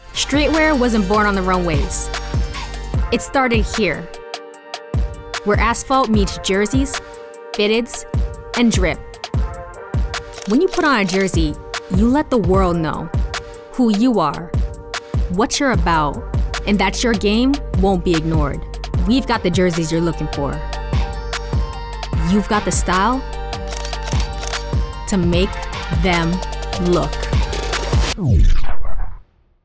E-Learning
Female
Approachable, Bubbly, Conversational, Cool, Streetwise, Young, Bright, Character, Children, Confident, Energetic, Engaging, Friendly, Natural, Smooth, Versatile, Warm
General American [native], New York [native], Caribbean (Jamaican/Grenadian), RP British, African (Zulu), American Southern (Alabama, etc.)
Microphone: Neumann TLM 103, Synco D2 shotgun microphone